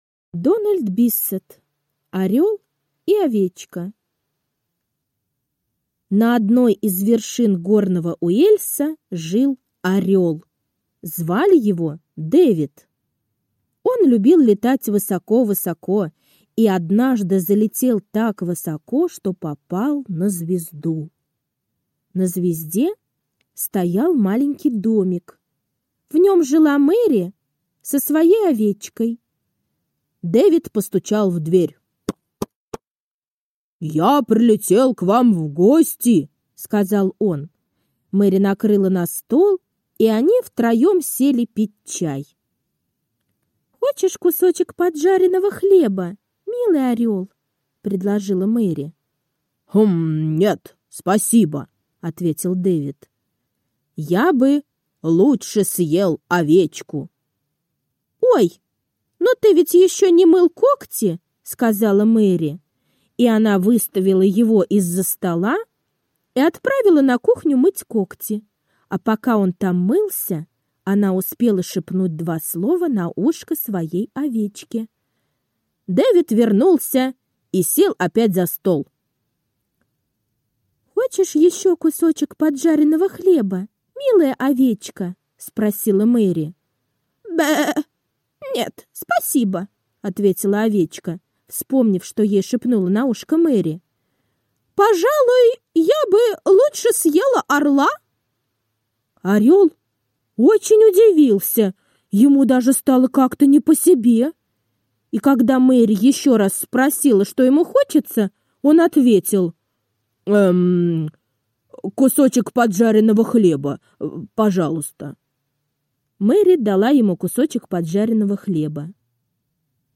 Орел и овечка — аудиосказка Дональда Биссета. Забавная история про орла, который залетел на звезду и пришел в гости к девочке Мэри.